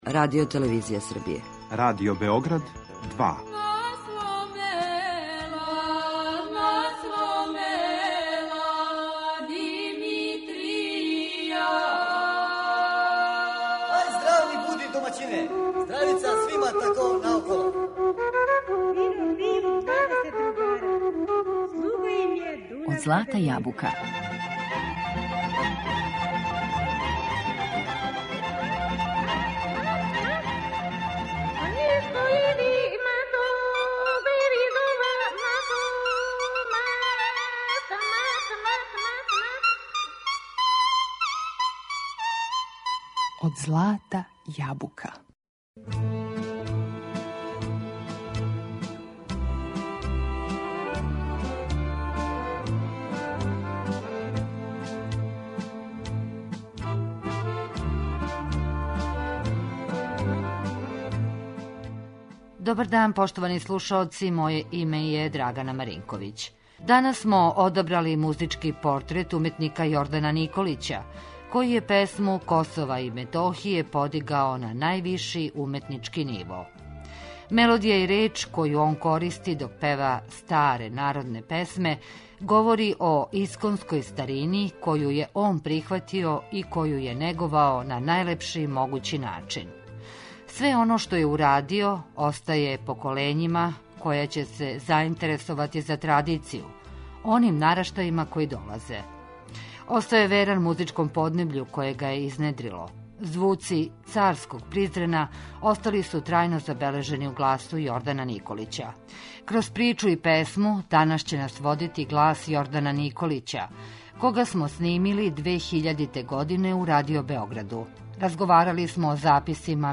Мелодије и речи које он користи док пева старе народне песме говоре о исконској старини коју је прихватио и коју је неговао на најлепши начин.